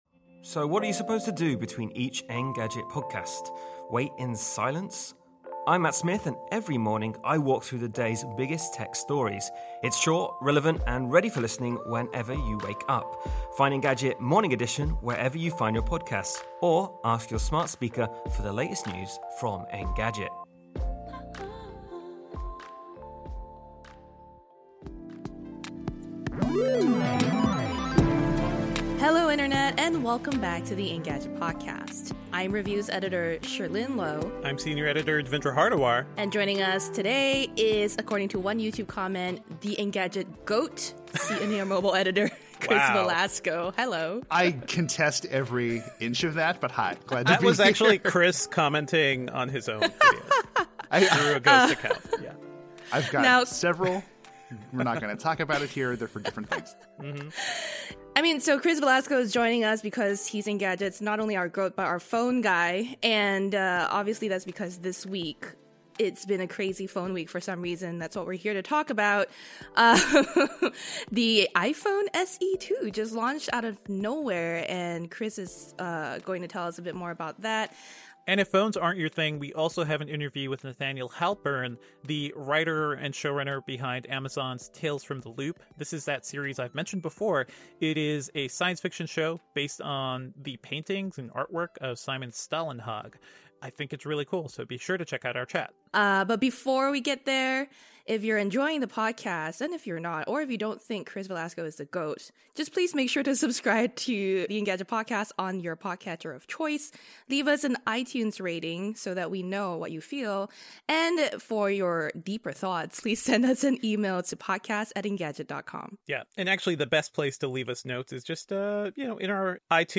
– 28:50 Working On – 42:20 Picks – 46:18 Tales from the Loop Interview – 54:27